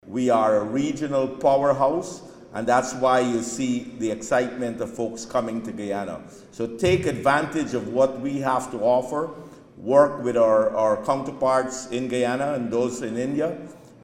The India/Guyana Chamber of Commerce (IGCC) convened its inaugural networking event on Wednesday evening at the Marriott Hotel, bringing together key stakeholders to explore investment opportunities.